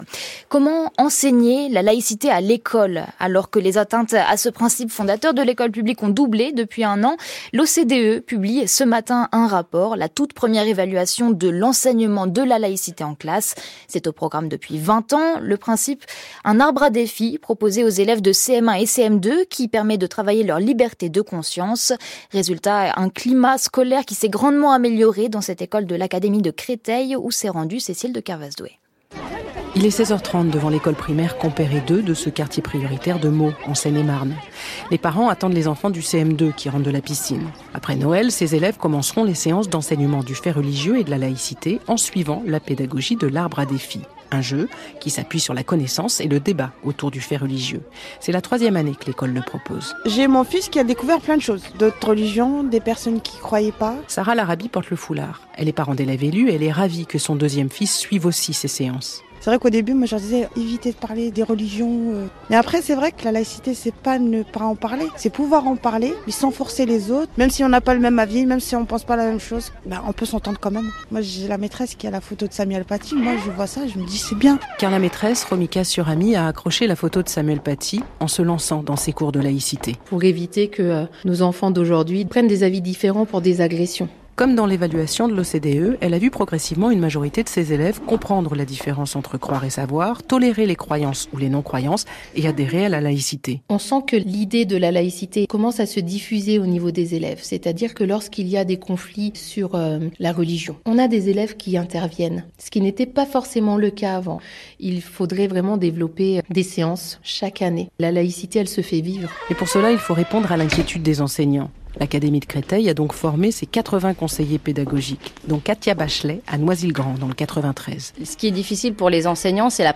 France-Culture-journal-de-8h-reportage-terrain.mp3